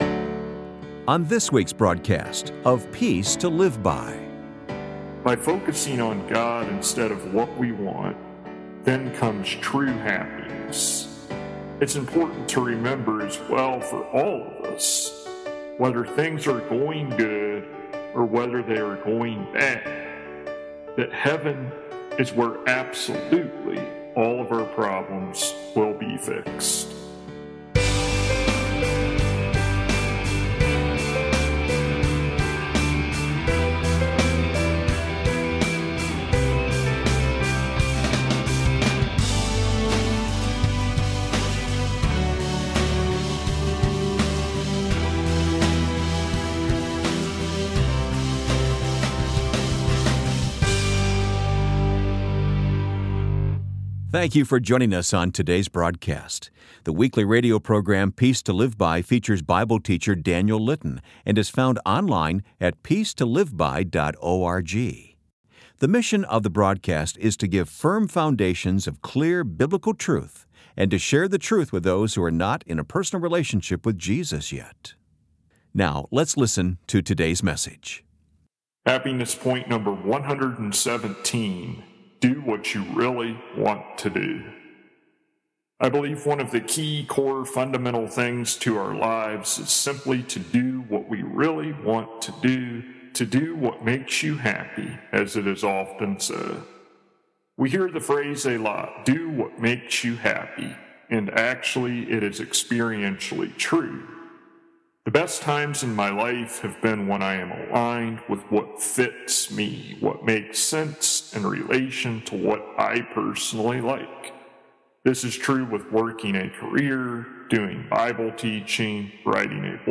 [Transcript may not match broadcasted sermon word for word]